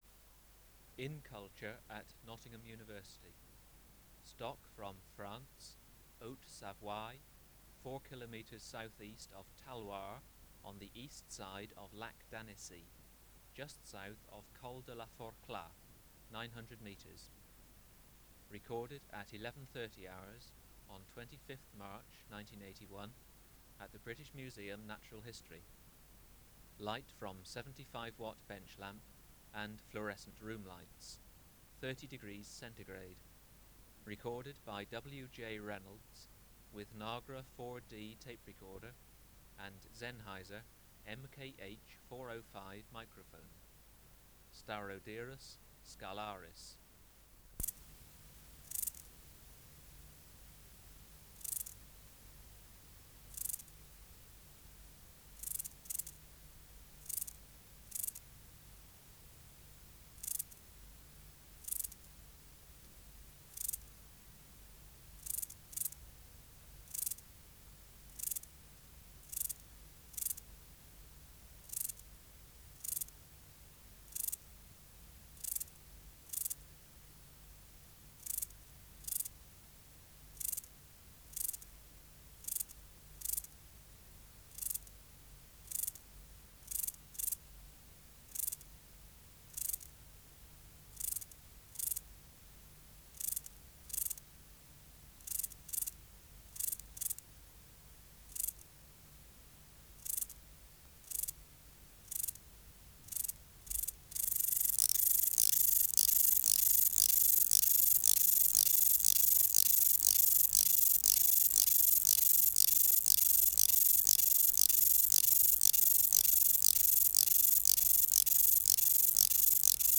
Recording Location: BMNH Acoustic Laboratory
Reference Signal: 1 kHz for 10 s
Substrate/Cage: Large recording cage
Female present but not near male. Right hind leg removed 1/2 hour before recording
Microphone & Power Supply: Sennheiser MKH 405 Distance from Subject (cm): 12
Recorder: Kudelski Nagra IV D (-17 dB at 50 Hz)